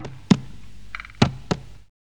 2606R ETHNO.wav